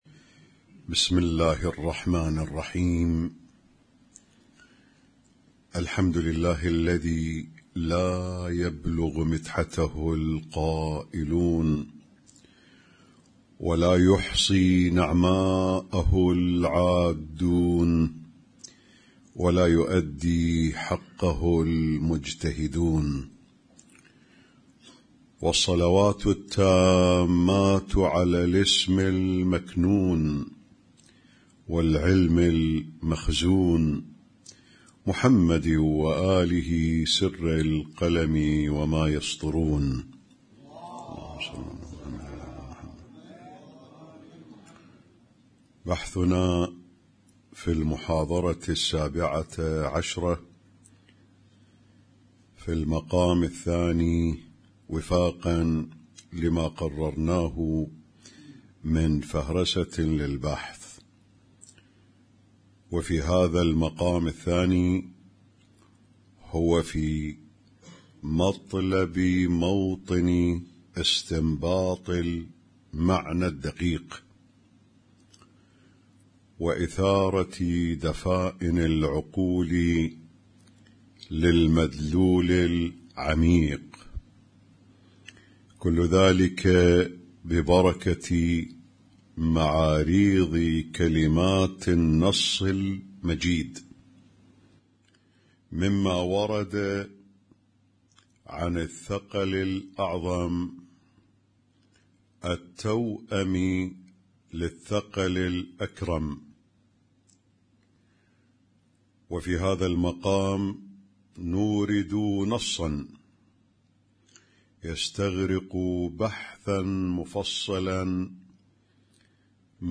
Husainyt Alnoor Rumaithiya Kuwait
اسم التصنيف: المـكتبة الصــوتيه >> الدروس الصوتية >> الرؤية المعرفية الهادفة